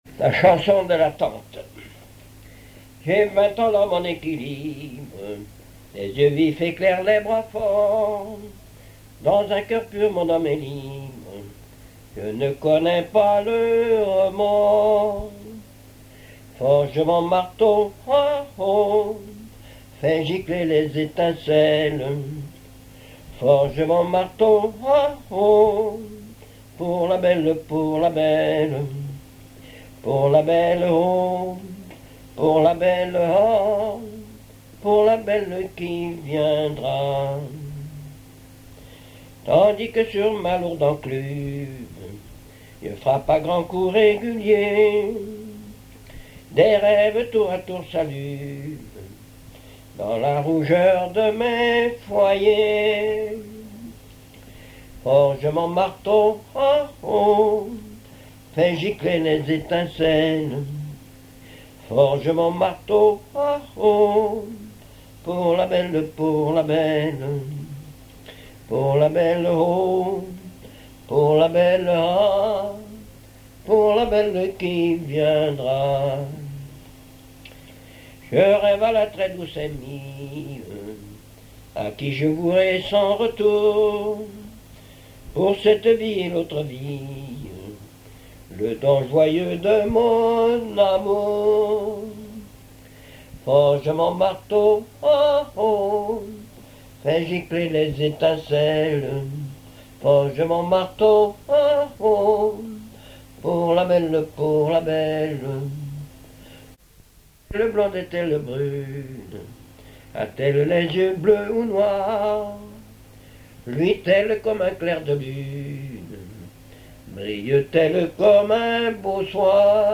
Genre strophique
chansons populaires et histoires drôles
Pièce musicale inédite